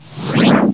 TELEPRT1.mp3